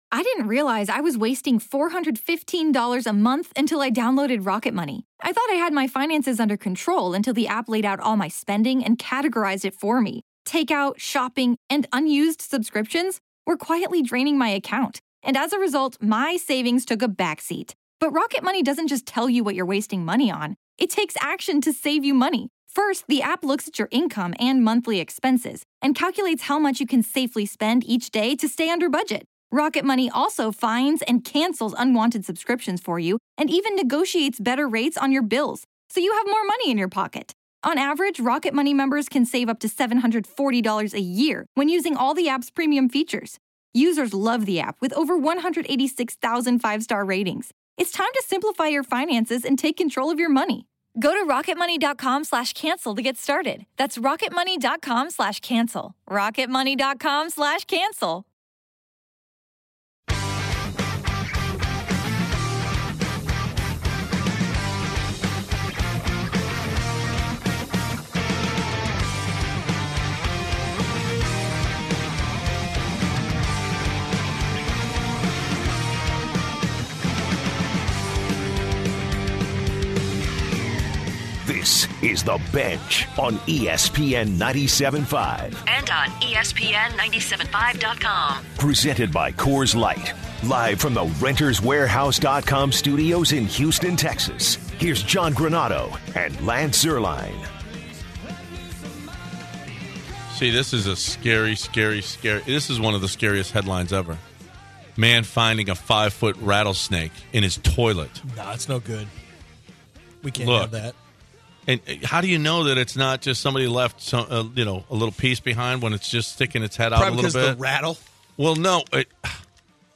In the second and final hour of the show, the guys start off with some random discussion as they talk about some of their favorite YouTube videos and TV series. As the show rolls on, they circle back to the NFL as they discuss PFF’s position rankings, give their opinions on some of the best players across the league at a variety of positions, and weigh in on a variety of QB situations across the league.